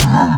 sounds / mob / camel / hurt2.ogg
hurt2.ogg